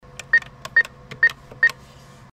Ввод пинкода в банкомате